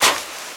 STEPS Sand, Walk 13.wav